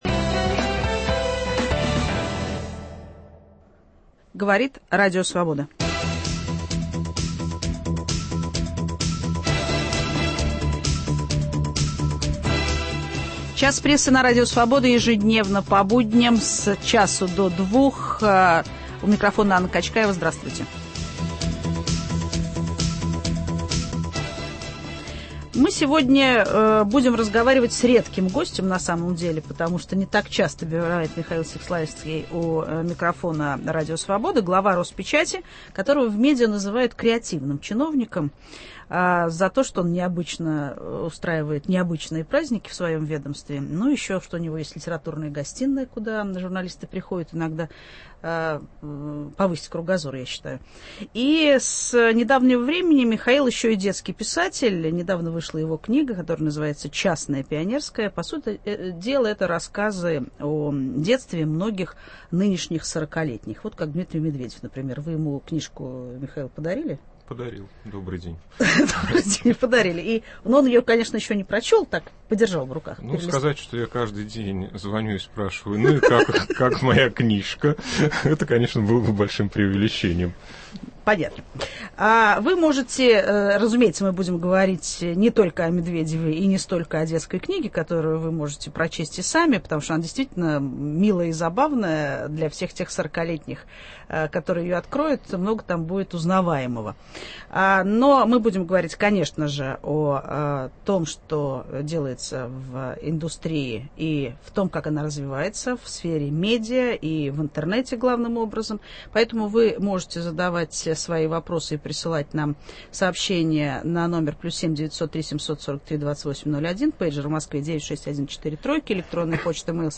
О перспективах новых медиа и интернета Анна Качкаева беседует с главой Роспечати Михаилом Сеславинским.